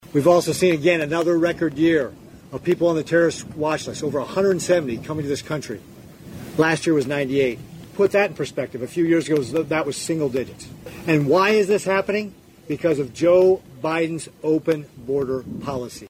RICKETTS EXPRESSED CONCERN ABOUT TERRORISTS COMING ACROSS THE SOUTHERN BORDER: